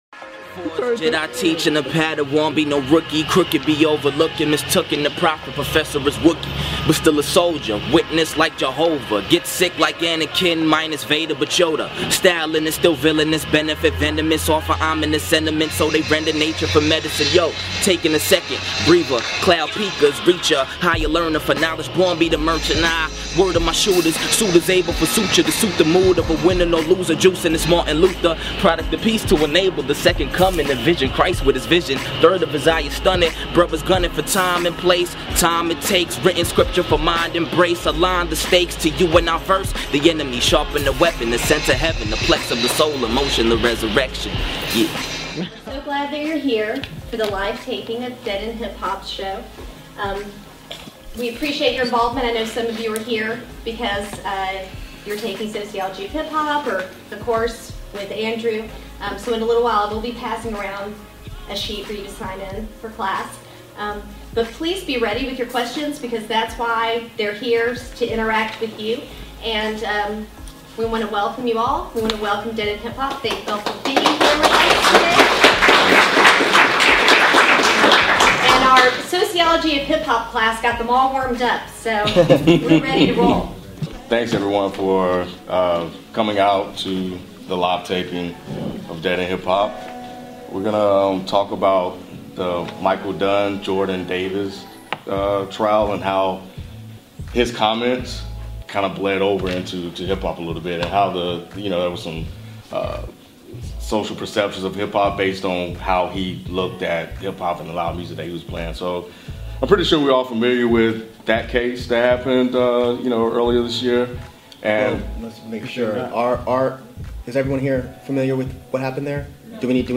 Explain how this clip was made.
DEHH Live Taping at Florida State University Pt.1